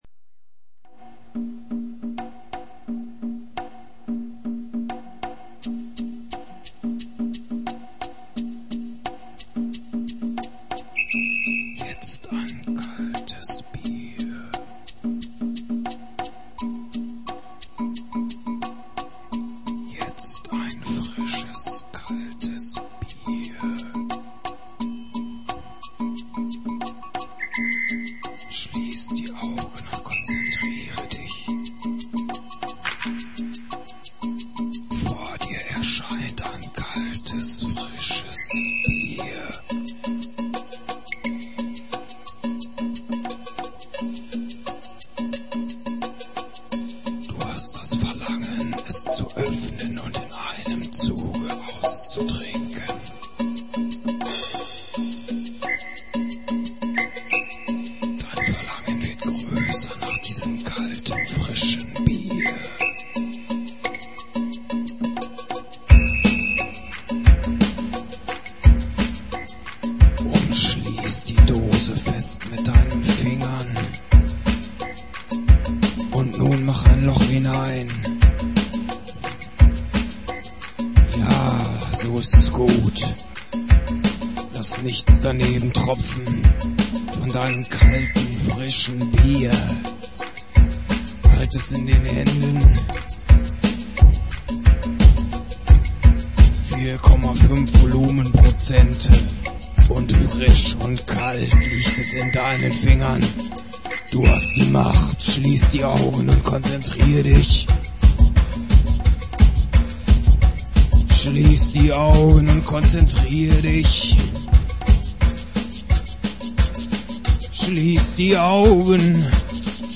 TypLP (Studio Recording)